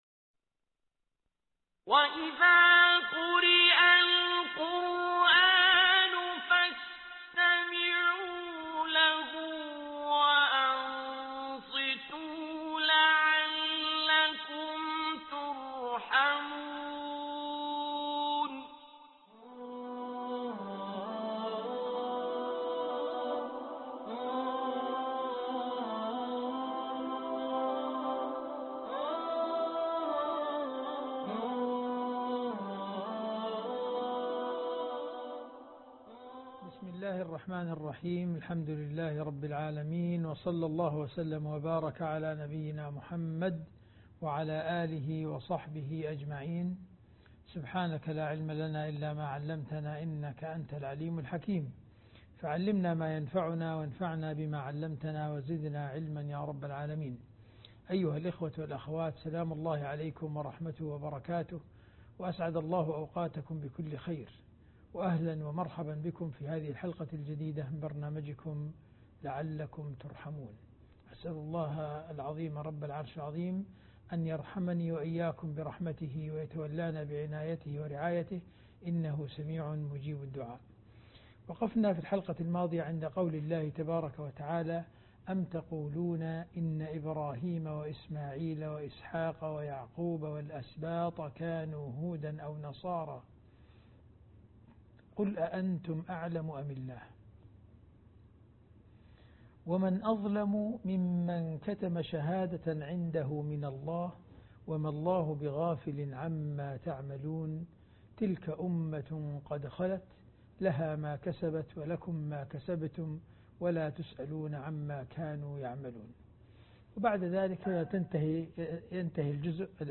الدرس 27 ( البقرة ) لعلكم ترحمون